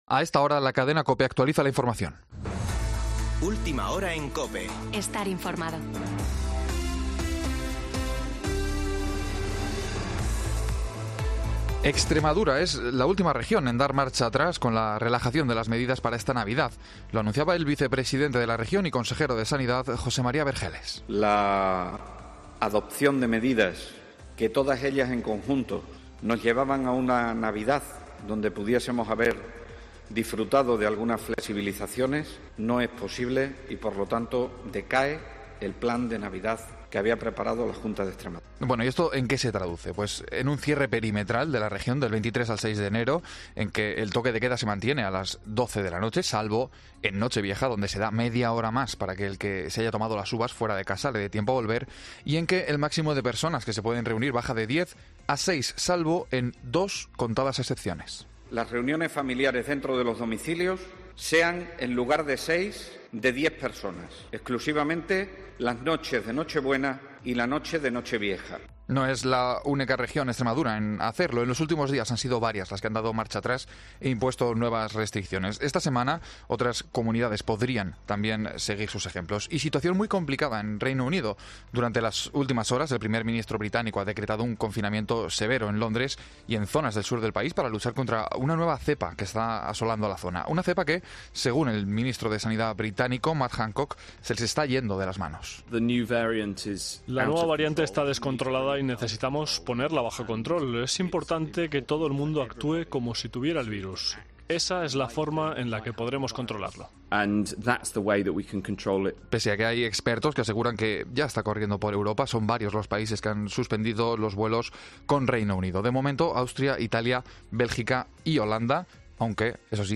AUDIO: Boletín de noticias de COPE del 20 de diciembre de 2020 a las 18.00 horas